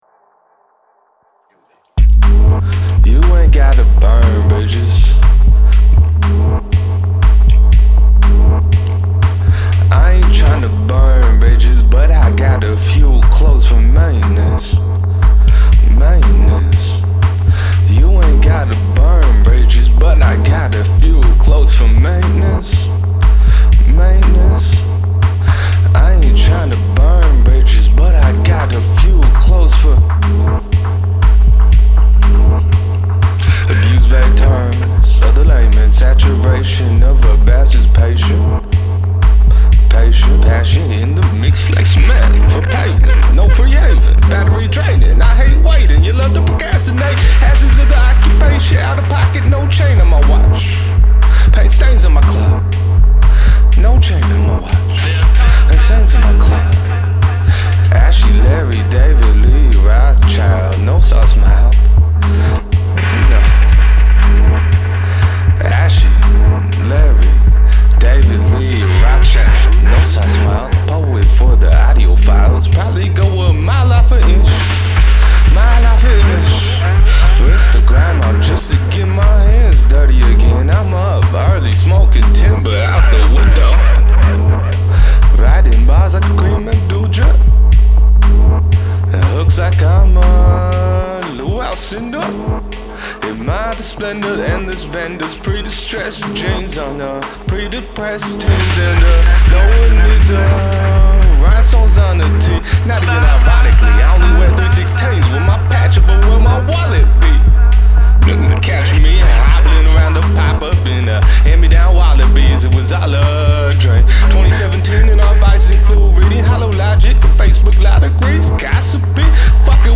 Recorded in one live take
stream of consciousness lyricism
heavy, meditative production